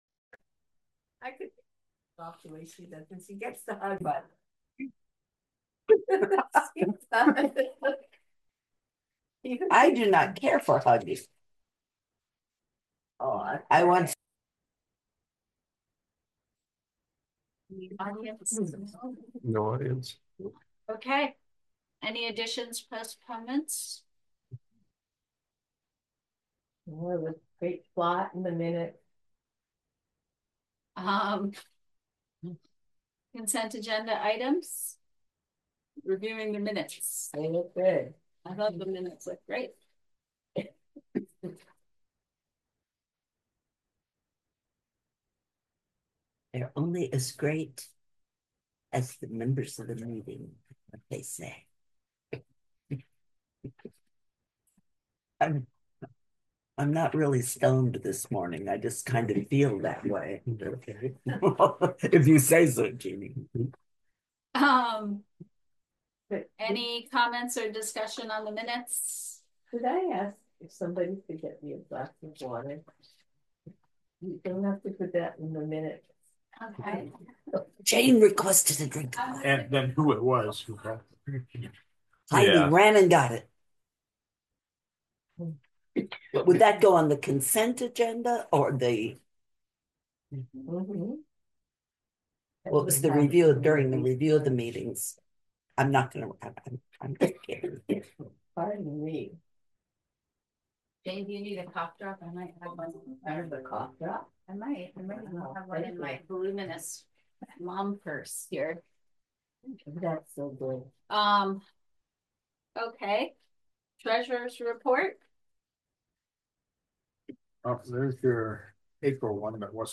Physical Meeting location: Roxbury Free Library, 1491 Roxbury Rd., Roxbury VT.